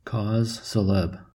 A cause célèbre (/ˌkɔːz səˈlɛb(rə)/
En-us-cause-célèbre.ogg.mp3